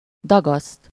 Ääntäminen
Ääntäminen France: IPA: [pet.ʁiʁ] Haettu sana löytyi näillä lähdekielillä: ranska Käännös Ääninäyte 1. gyúr 2. dagaszt Määritelmät Verbit Détremper de la farine avec de l’ eau , la malaxer et en faire de la pâte .